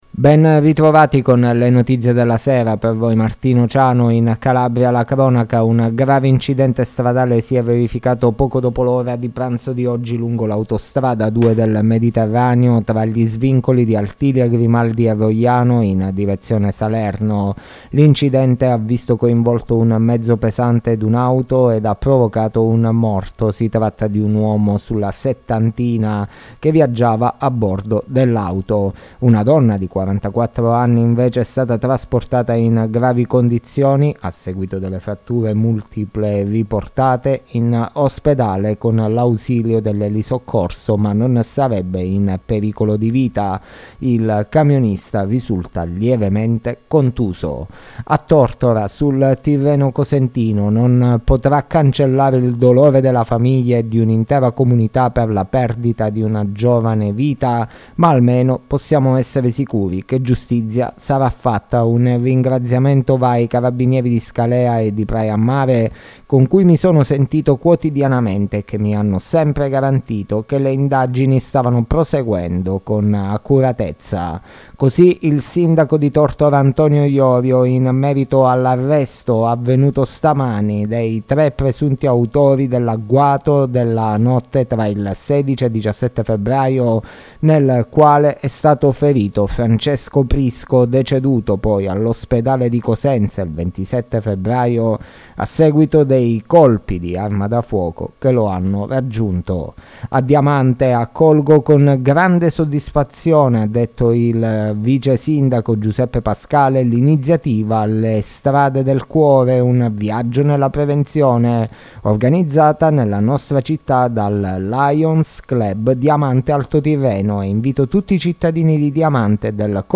LE NOTIZIE DELLA SERA DI GIOVEDì 16 MARZO 2023